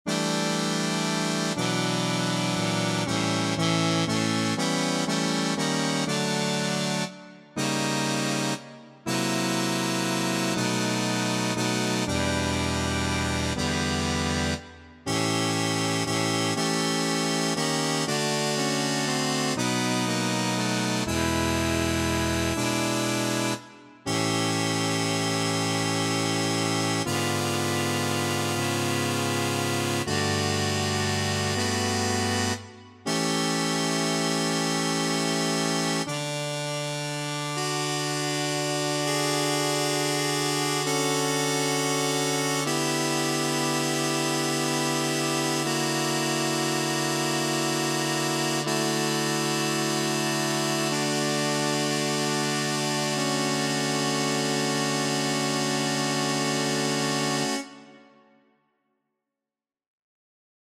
Key written in: D Major
How many parts: 4
Type: Barbershop
Comments: Angsty ass piece
All Parts mix: